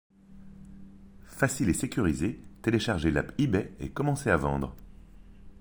- Basse